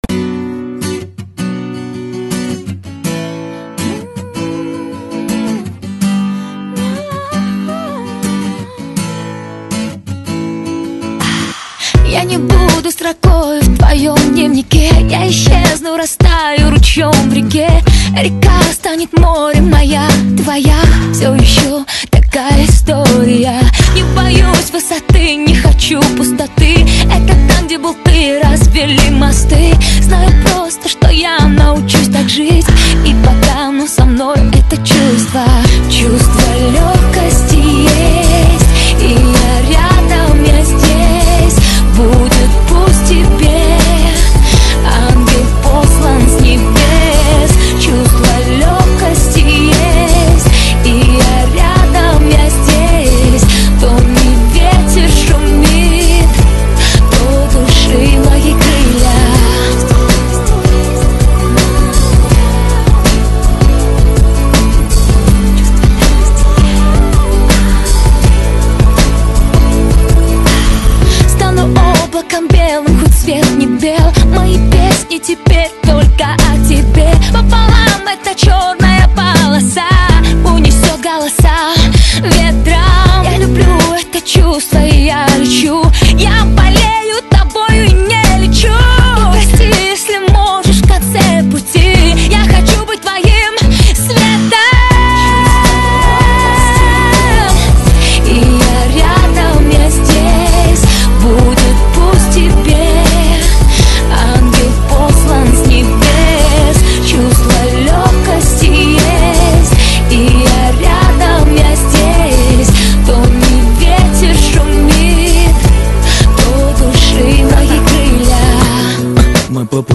Главная » Популярная музыка